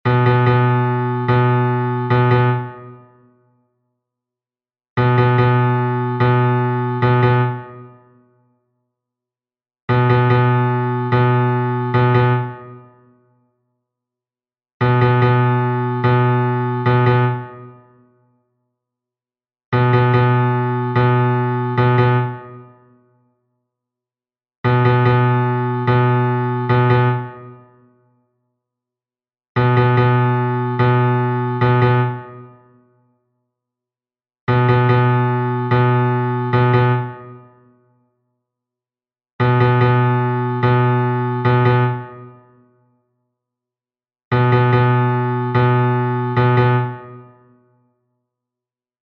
New rhythmic patterns
The bar of "The Game of Thrones" score is 6/8, that we already know.
ritmo2juegotrono.mp3